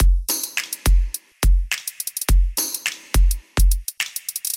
Tag: 105 bpm House Loops Drum Loops 787.67 KB wav Key : F